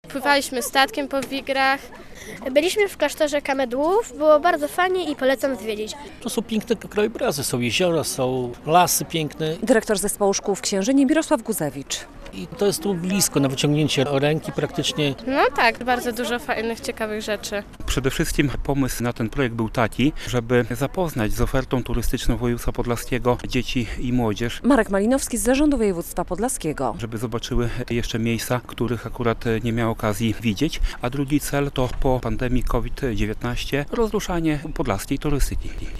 6 tys. uczniów szkół podstawowych zwiedziło region w ramach programu Poznajemy Podlaskie, a do branży turystycznej w Podlaskiem wpłynęło 1,5 mln zł - podsumowano w piątek (23.06) na konferencji prasowej w Białymstoku.
relacja